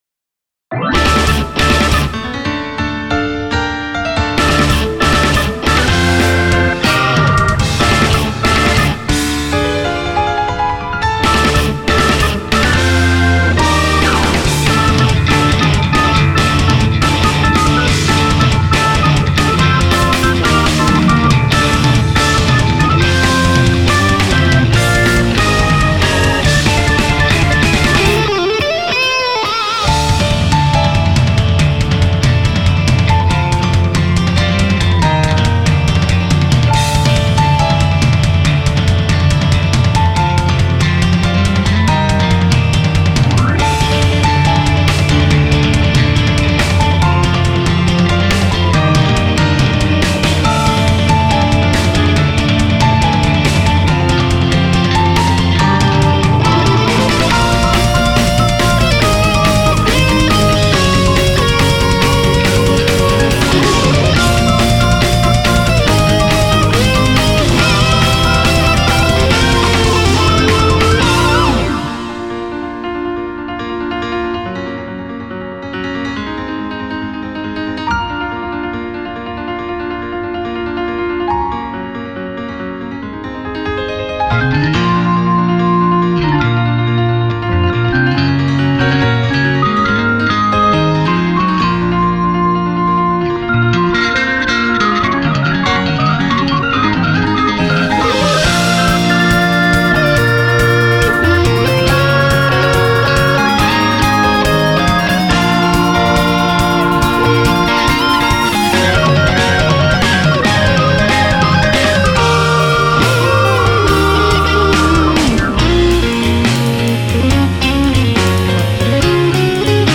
guitar, bass
piano
organ